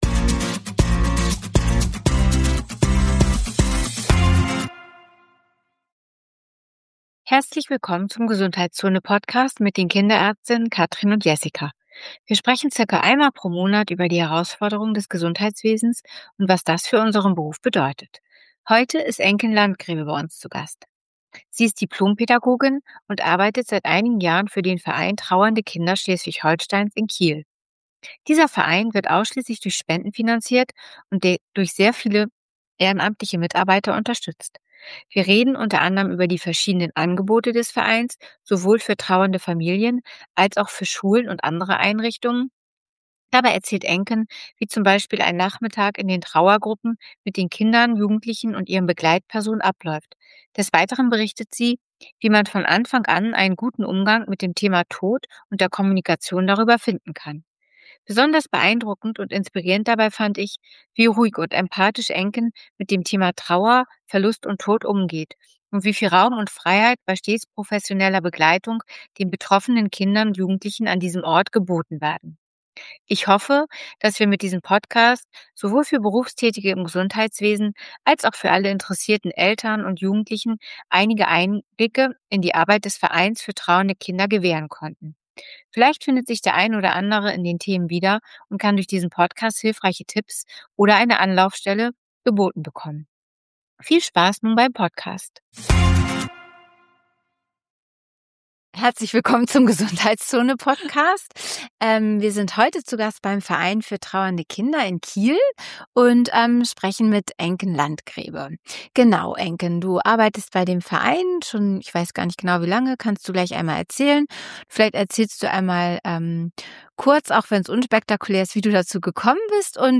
G#24 Wer der Trauer Raum gibt, schafft Platz für das Lachen. ~ GESUNDHEITSZONE // zwei Kinderärzte über Probleme und Lösungen in ihrem Job Podcast